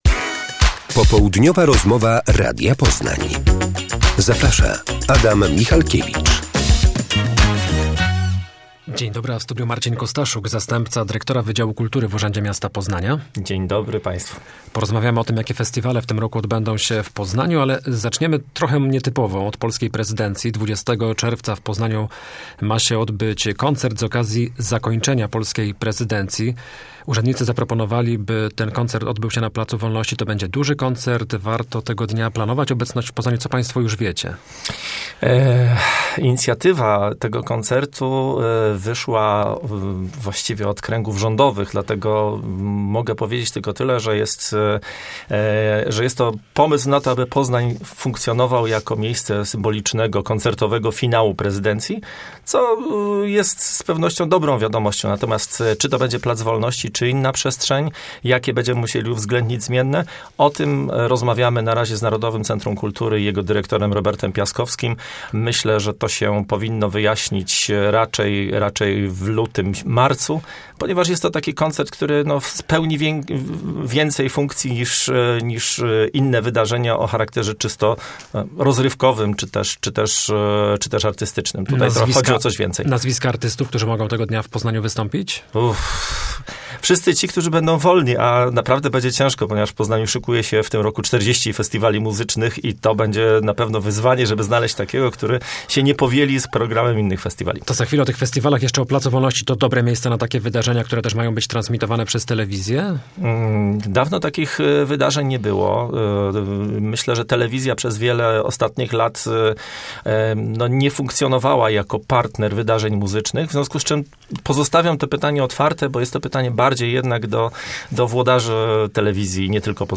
Popołudniowej Rozmowie